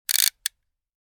Short-fast-mechanical-winding-sound-effect.mp3